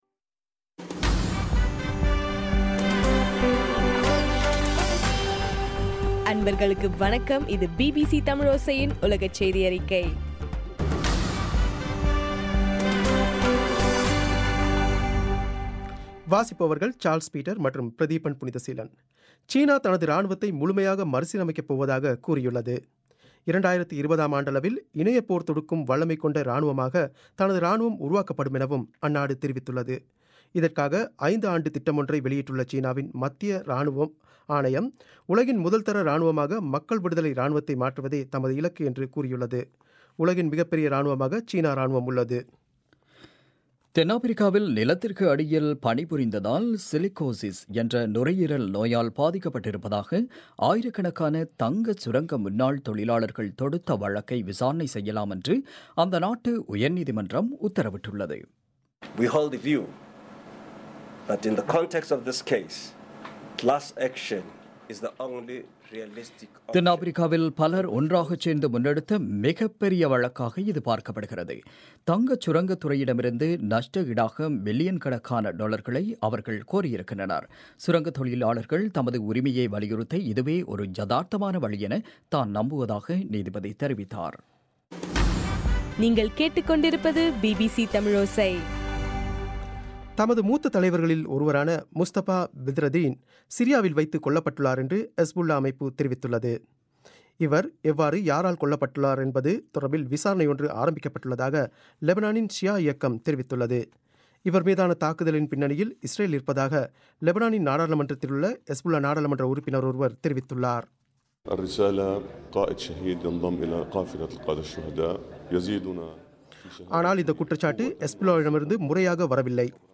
மே 13 பிபிசியின் உலகச் செய்திகள்